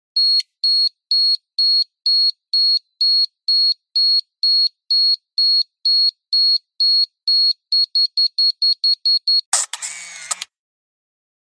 ピーピーピーピピピピというリズミカルなビープ音とカシャッというシャッターサウンドが織り成す、躍動感溢れるカメラ音です。